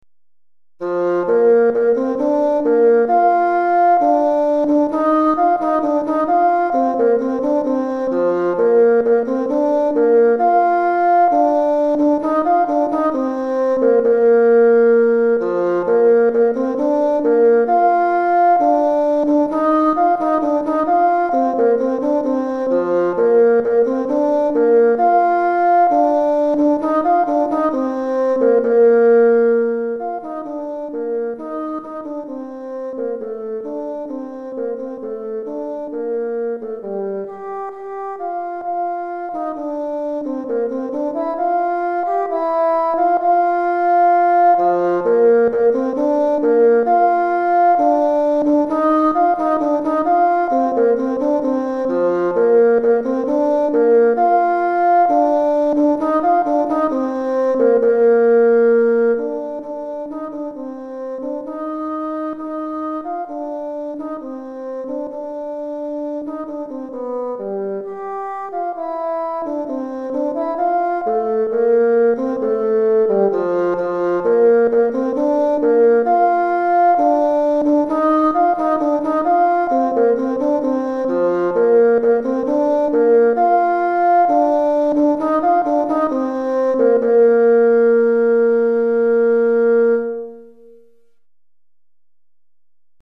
Basson Solo